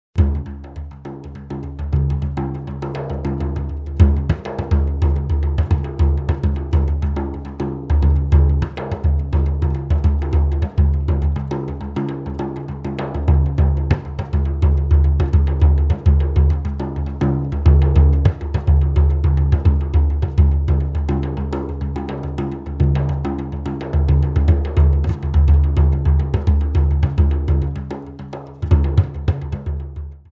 bodhran